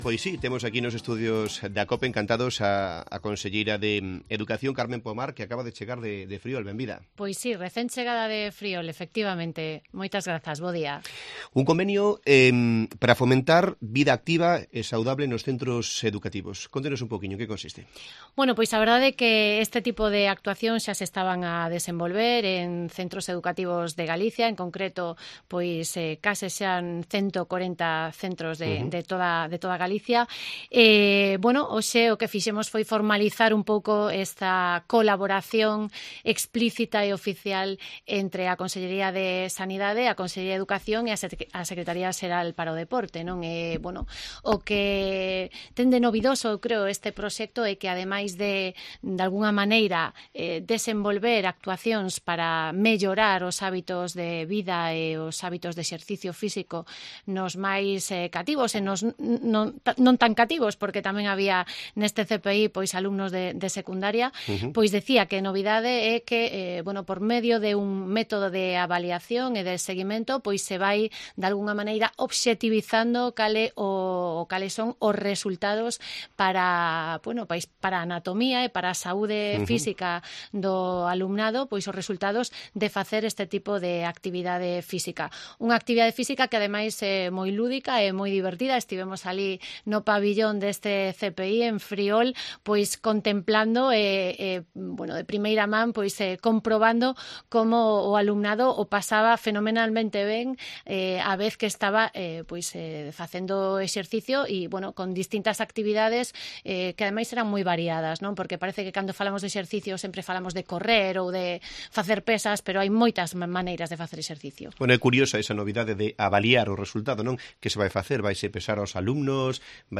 Escucha la entrevista completa a la conselleira de Educación en Cope Lugo